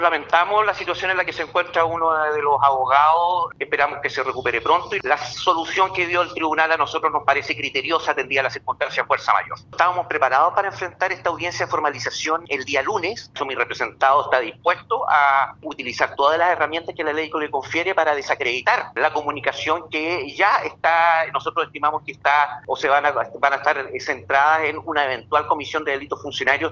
El exfiscal, Andrés Cruz, quien representa al concejal del PS, Eduardo Araya, señaló que, no obstante, la nueva fecha donde se formalizará la indagatoria, como defensa, están preparados para enfrentar dicha instancia.